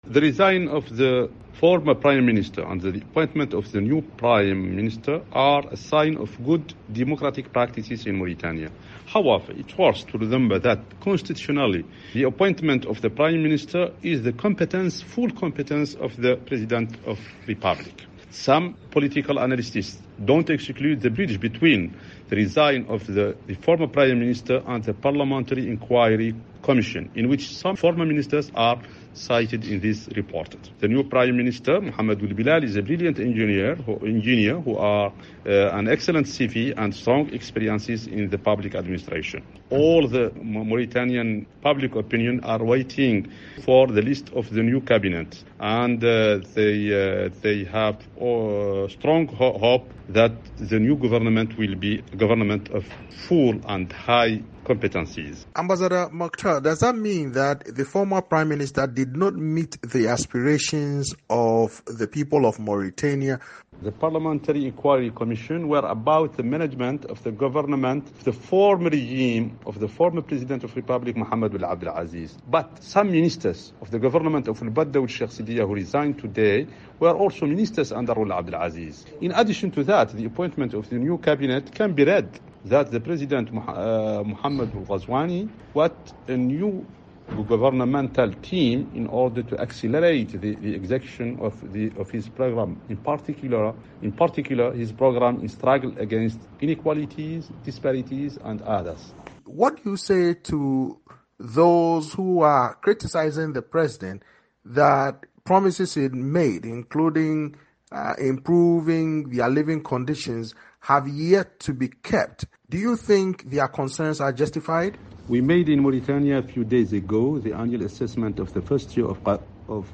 spoke to Moctar Ould Dahi, a former Mauritanian envoy to Italy, about the appointment.